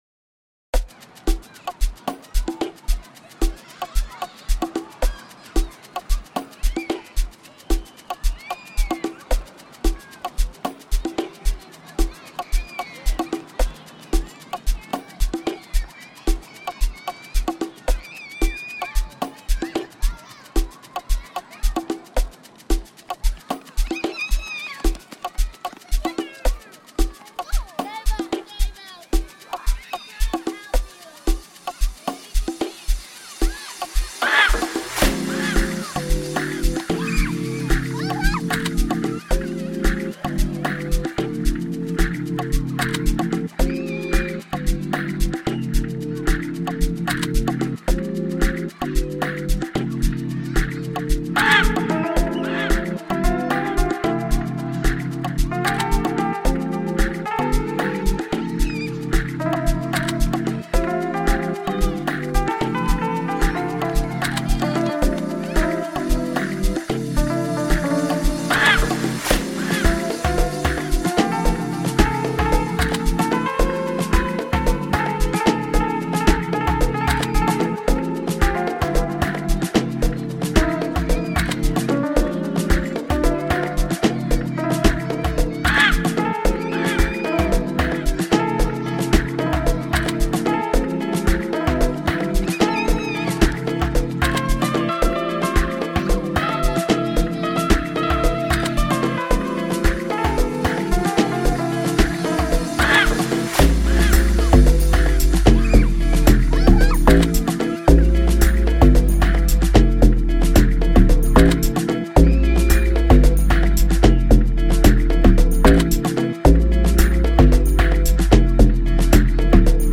Amapiano Mix , Dj Mix